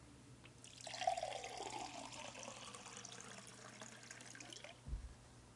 皿和浇注
描述：这是我用佳能GL1和Audiotechnica猎枪式麦克风录制的一个片段（不确定型号，我肯定是一个便宜的）。这个片段包含了我用叉子在碗里碰来碰去的声音（听起来像是有人在吃东西），以及把水倒进杯子和碗里的声音。由于音频很低，可能需要增加增益。
声道立体声